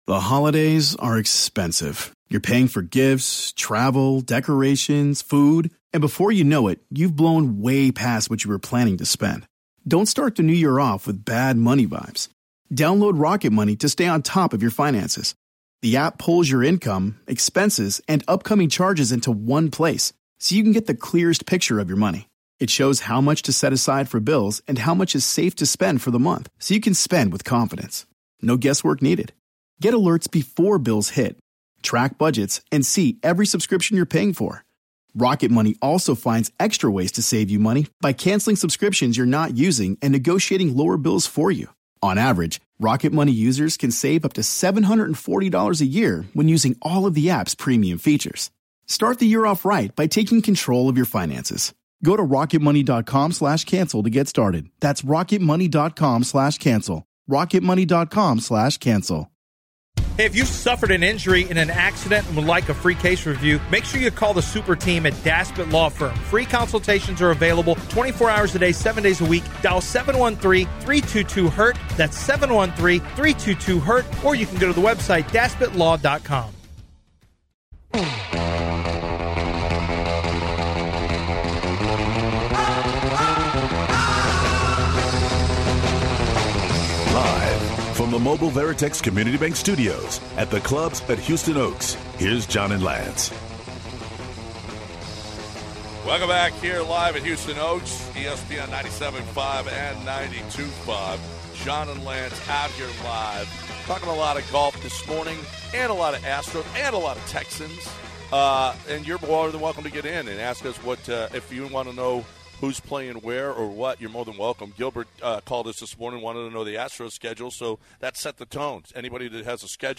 We end it off with an interview